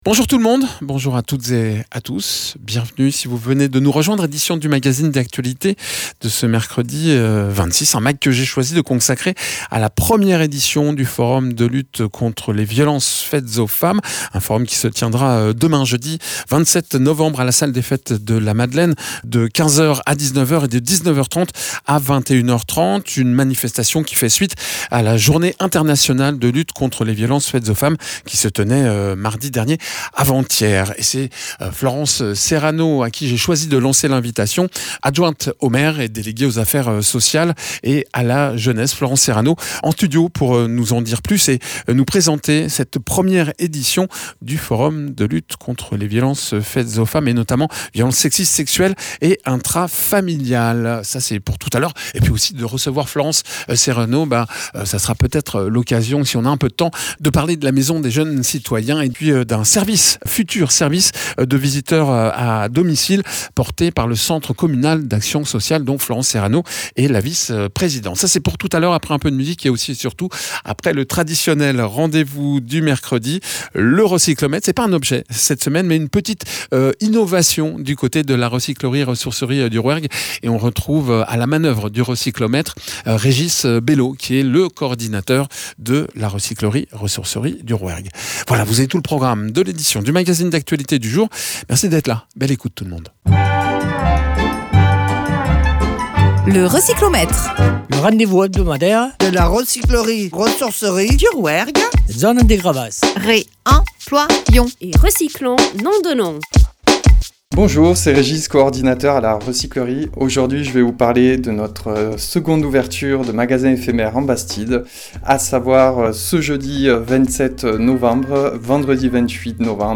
Suivi d’un entretien avec Florence Serrano, sur les préventions.
Florence Serrano, adjointe au maire déléguée aux affaires sociales et à la jeunesse, vice-présidente du CCAS, référente CLSPD